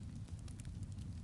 篝火噼啪声 " 篝火噼啪声1
描述：营火的噼啪声的简短片段。
Tag: 噼里啪啦 篝火 木材